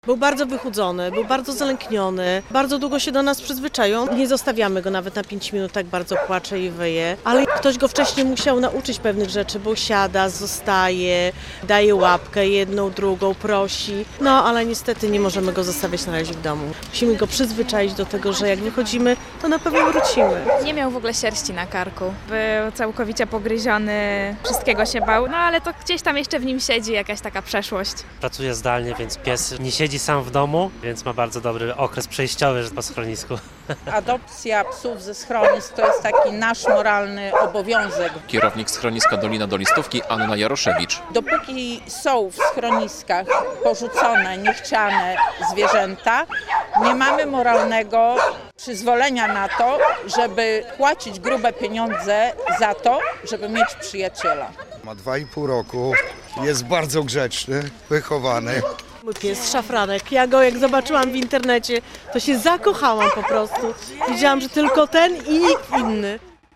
Parada Adopciaków - relacja